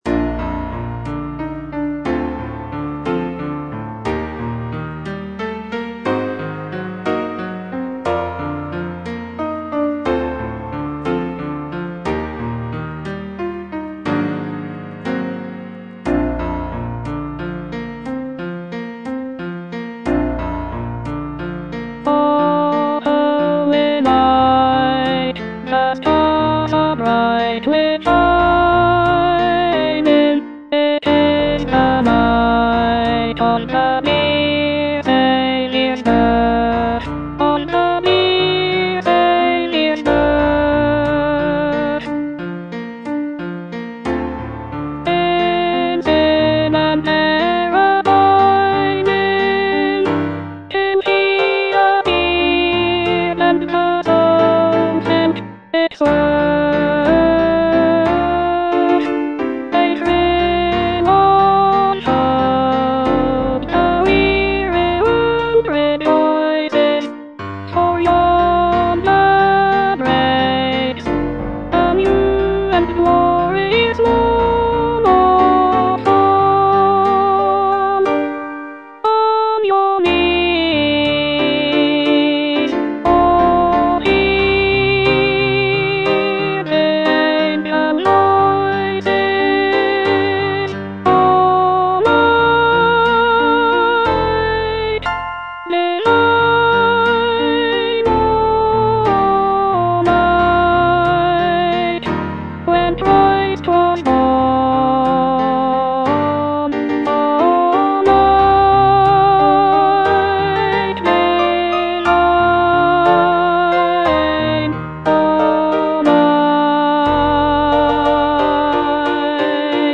Alto (Voice with metronome)